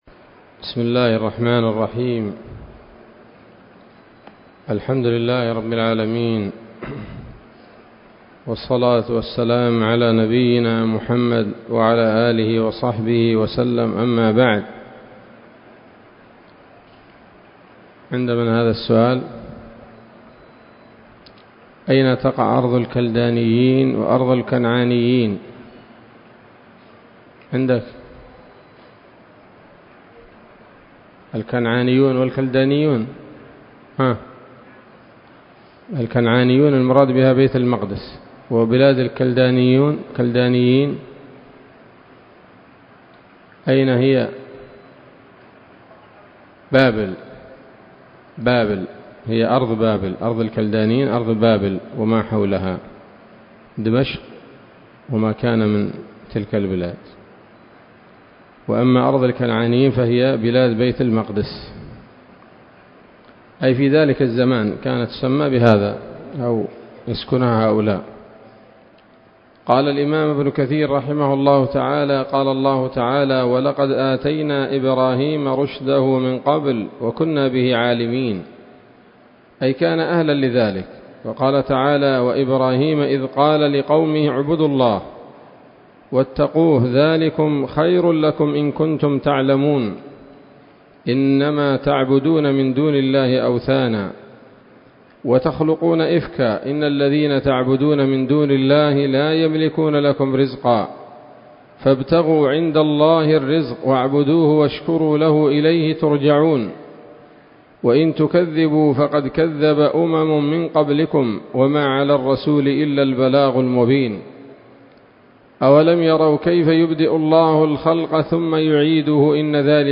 الدرس الأربعون من قصص الأنبياء لابن كثير رحمه الله تعالى